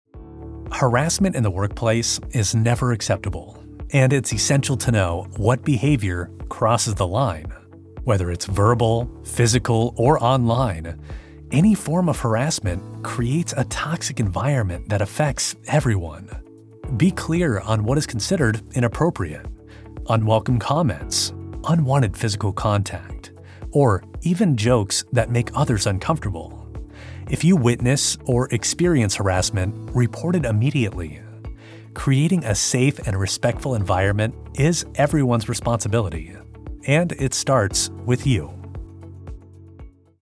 NARRATION
A corporate trainer who makes even the toughest compliance topics digestible, ensuring employees feel confident and prepared to apply the rules in real-life scenarios.